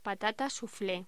Locución: Patata souflé